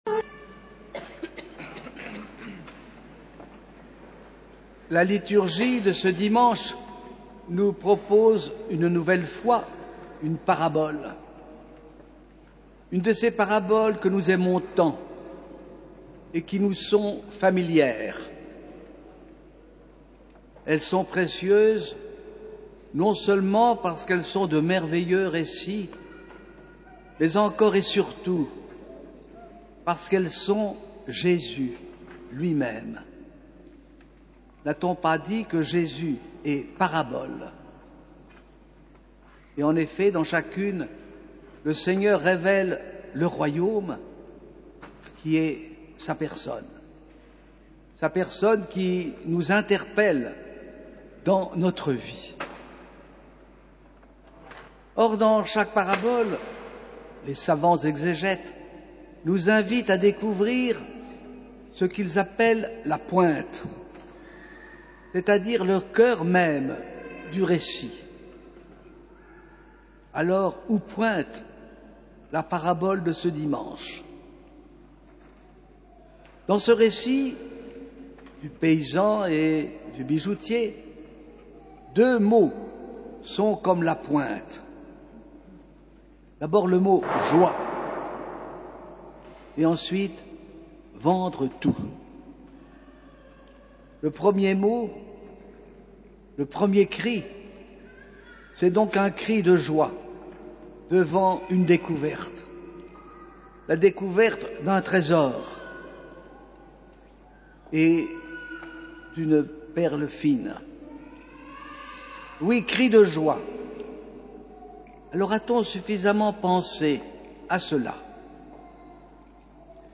homélies